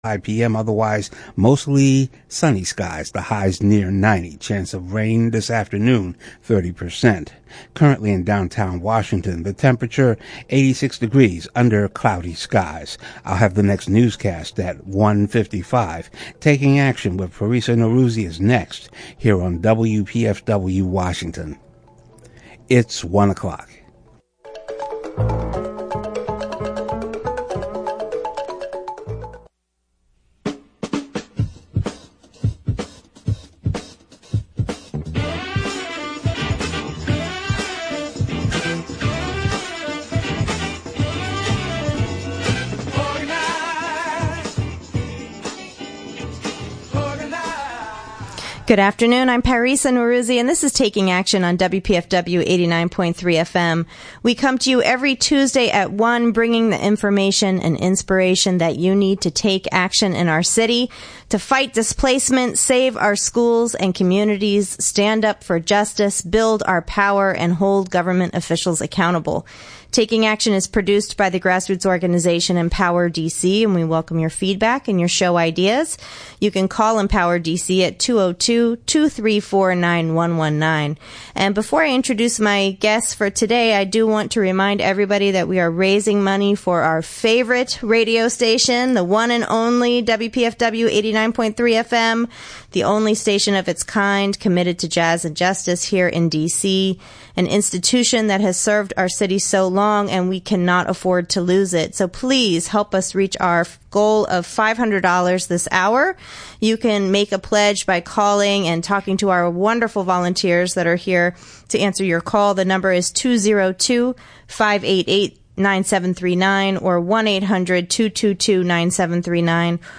UNA National Capital Area Chapter Talks Human Rights on the Radio - UNA-USA
UNA-USA's National Capital Area Chapter recently joined WPFW 89.3FM's Taking Action show to talk about their chapter's work to uphold human rights for all Washingtonians and all citizens of the world. This work starts with their local Universal Periodic Review (UPR) consultation.